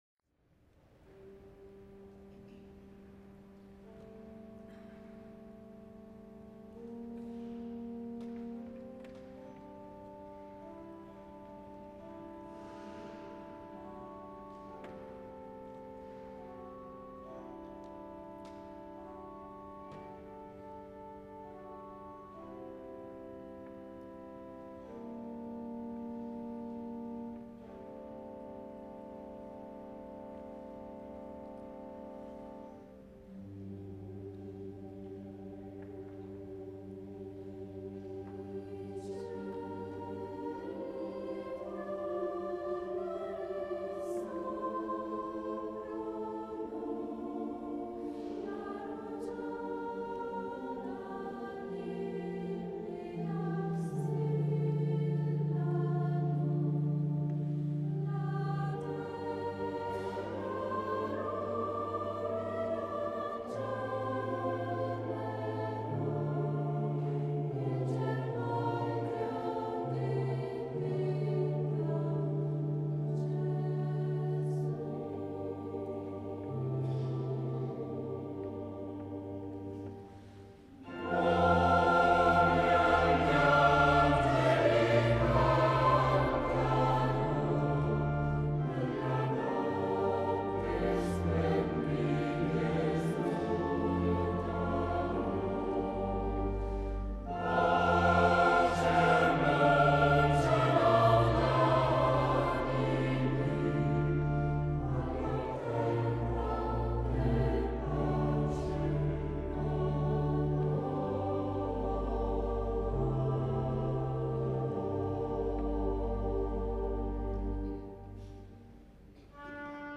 Elevazioni Musicali > 2000 > 2001
S. Alessandro in Colonna